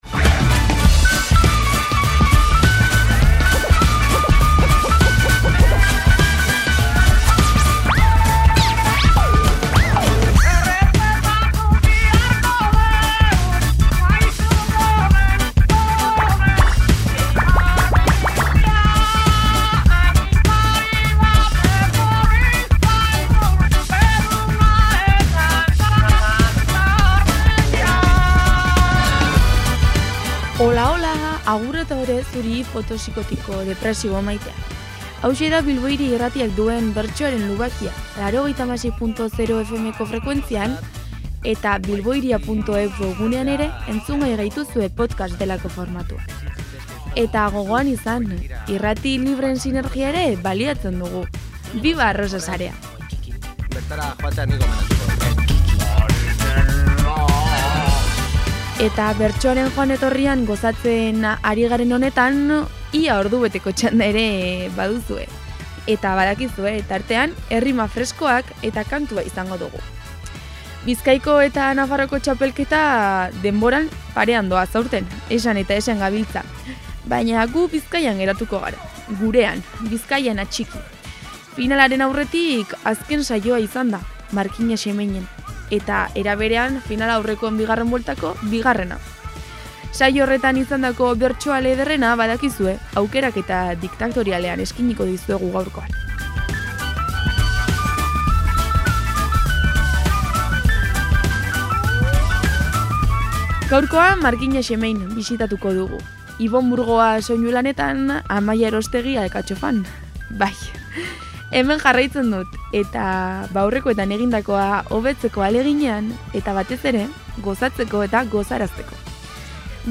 Bizkaiko Bertsolari Txapelketak egin du finala aurreko azken geldialdia Markina-Xemeinen. Bertan izan zen azken finalaurrekoa. Saio horretan izandako bertsoen aukeraketa diktatoriala duzue gaurkoan entzungai.